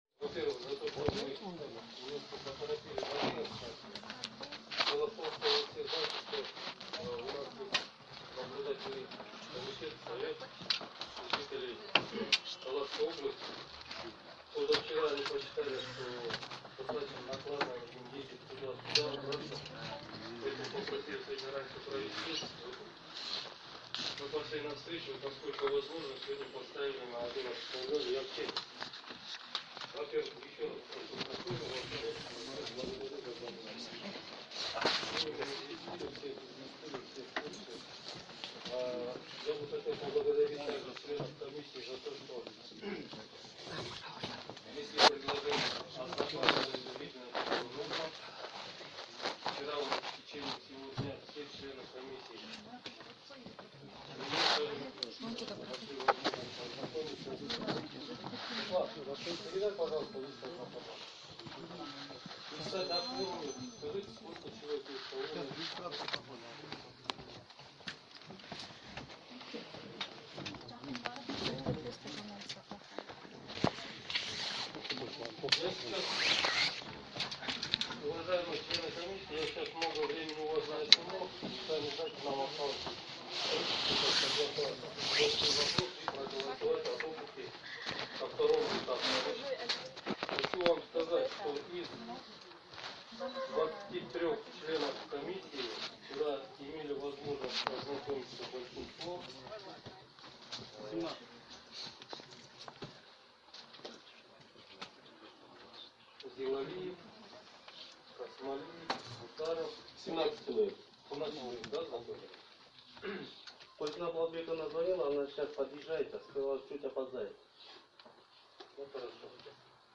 Диалог Данияра Тербишалиева и Дуйшенбека Зилалиева